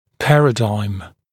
[‘pærədaɪm][‘пэрэдайм]парадигма, система взглядов и понятий